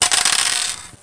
diceroll.mp3